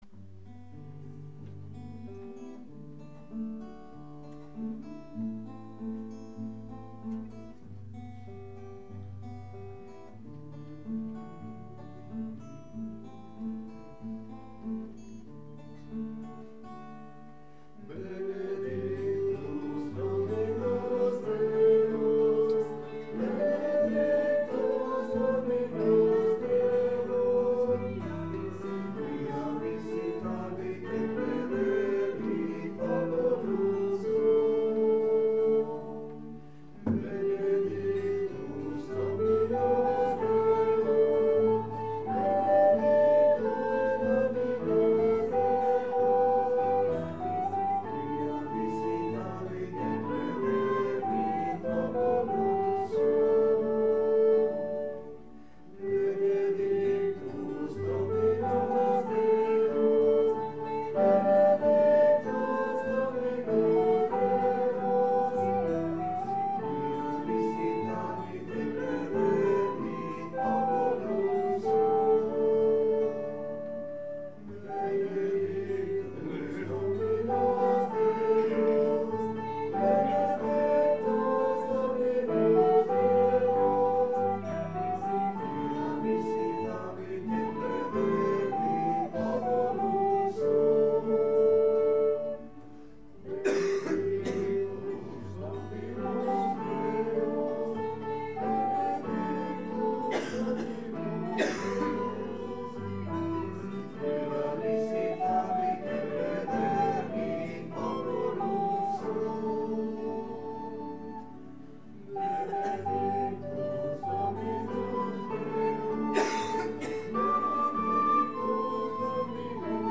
Pregària de Taizé
Capella dels Salesians - Diumenge 25 de maig de 2014